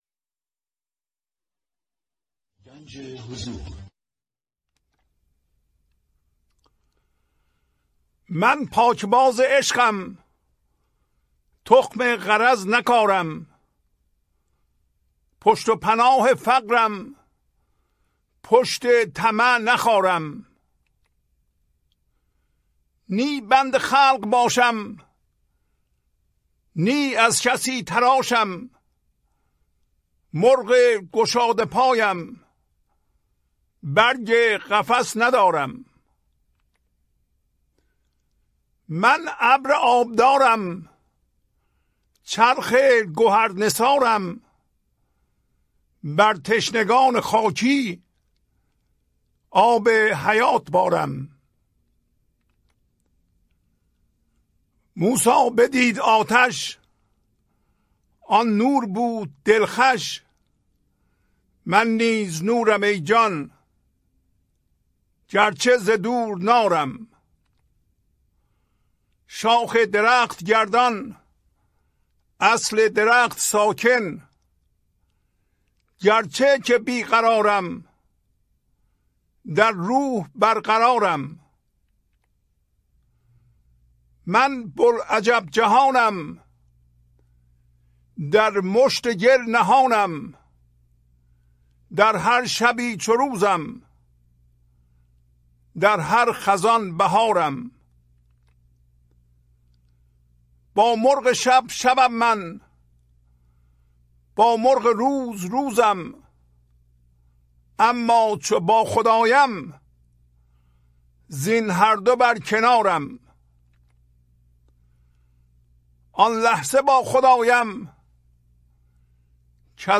خوانش تمام ابیات این برنامه - فایل صوتی
1042-Poems-Voice.mp3